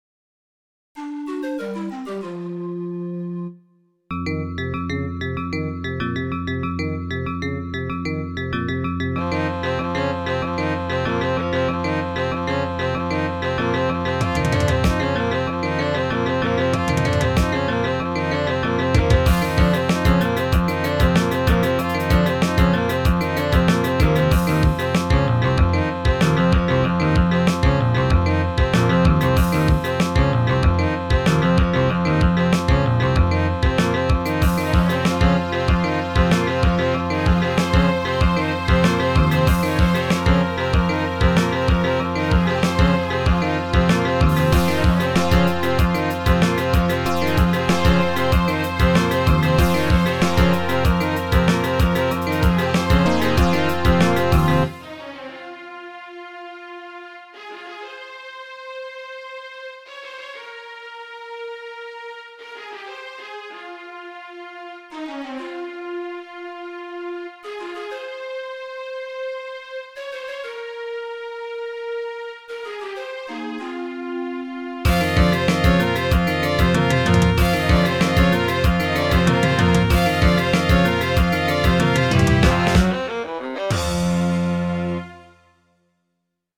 PROGRESSIVE ROCK MUSIC ; PAGAN MUSIC